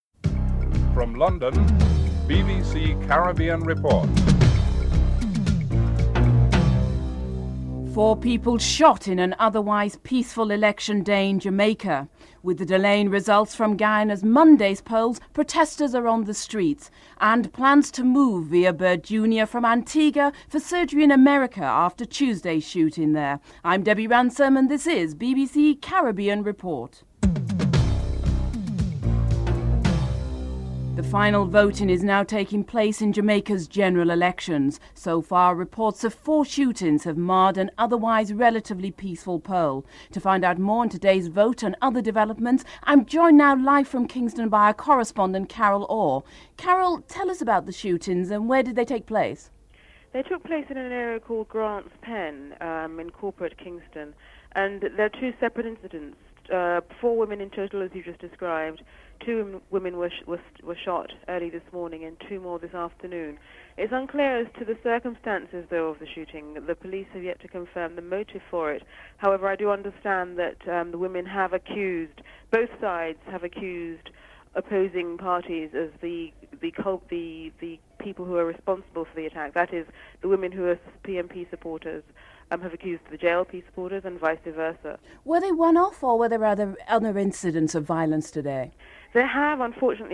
1. Headlines (00:00-00:30)